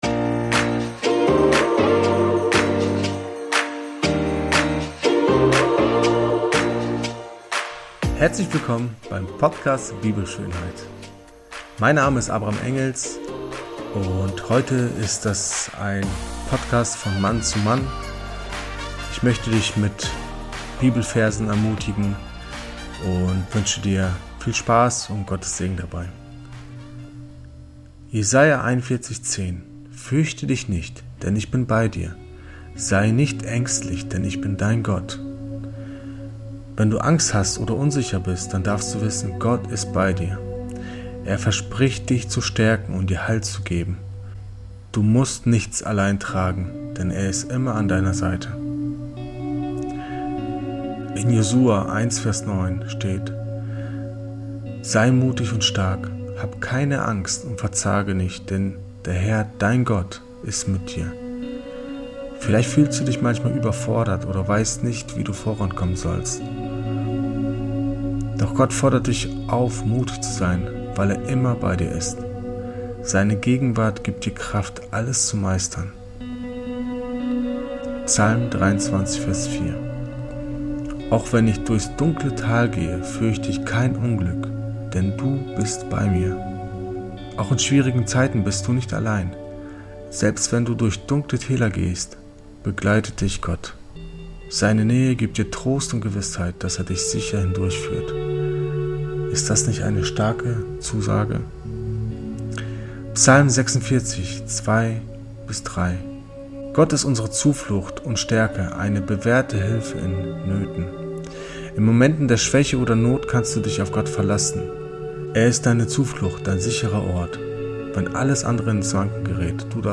in der er ermutigende Bibelverse zur Stärkung und Erbauung vorliest. Diese Worte sind eine kraftvolle Erinnerung an Gottes Treue, seine Führung und die Identität, die wir in Christus haben.